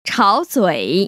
[chăo//zuĭ] 차오쭈이  ▶